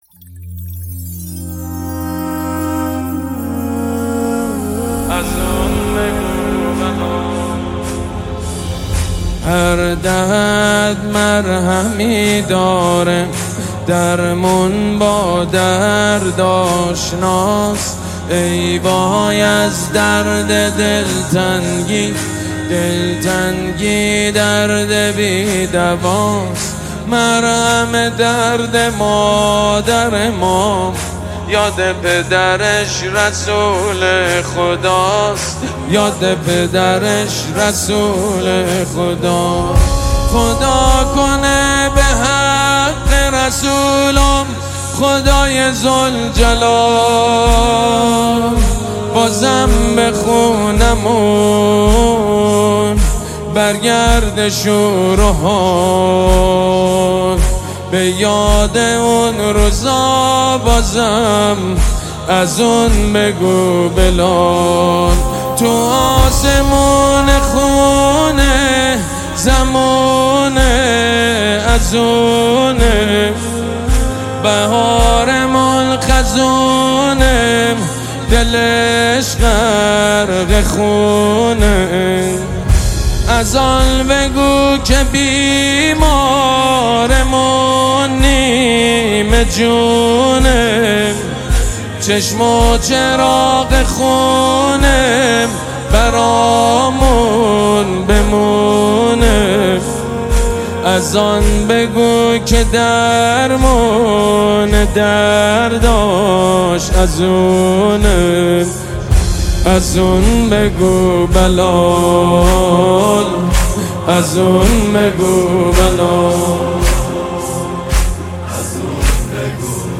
نماهنگ استودیویی